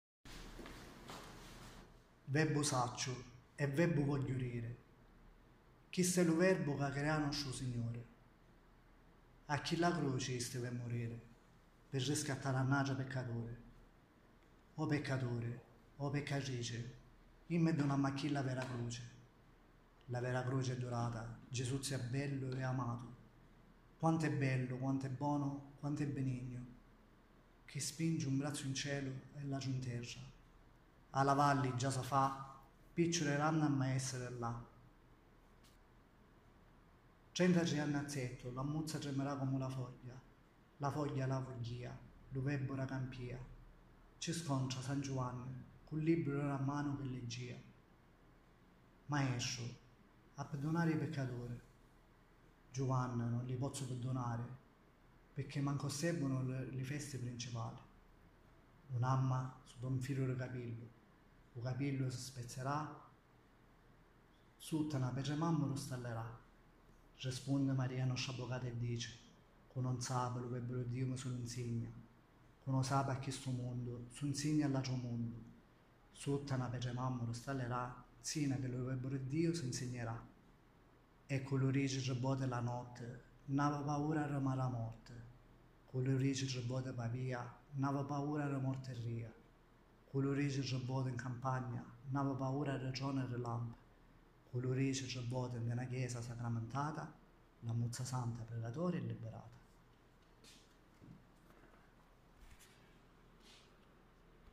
PREGHIERE E CANTI RELIGIOSI